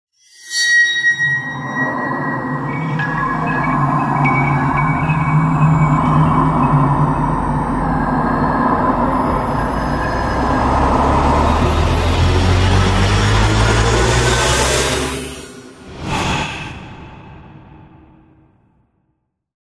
Sound Effect Horror Intro 1.mp3